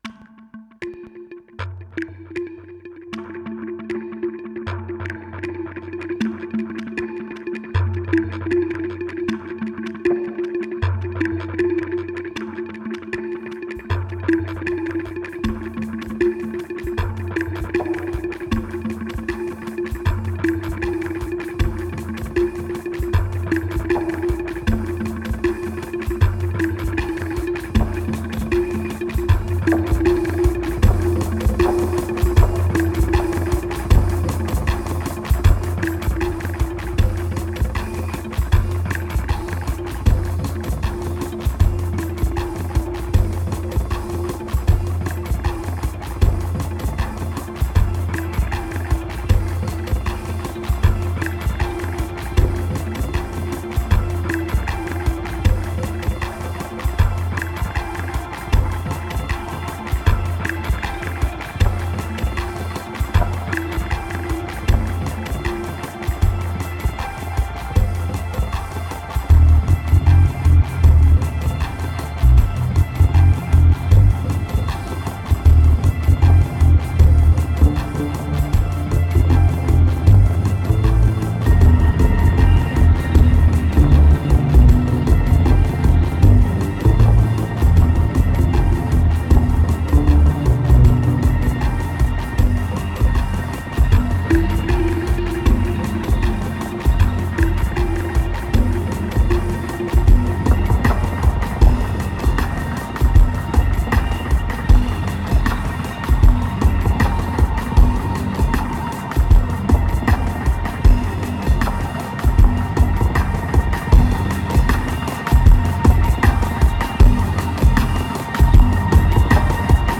2082📈 - 17%🤔 - 78BPM🔊 - 2011-04-10📅 - -97🌟